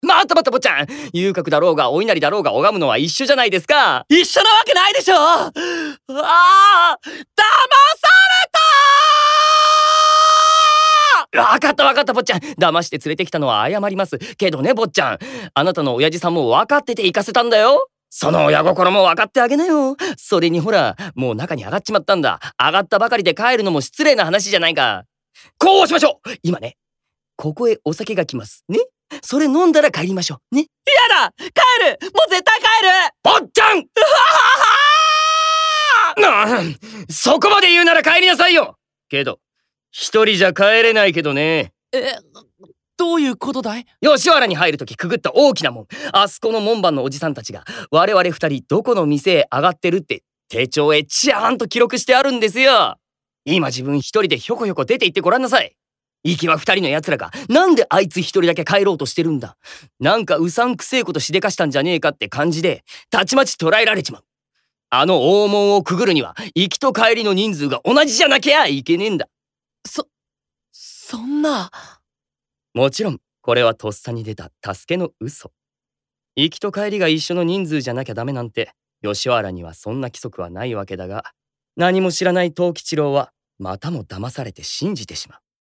色男×落語で彩るドラマCD『ハンサム落語』シリーズが発売決定！